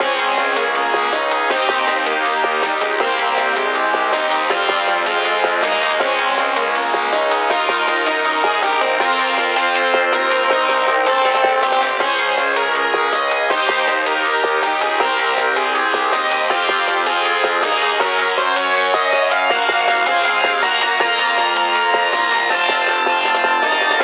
l’une des sonneries du téléphone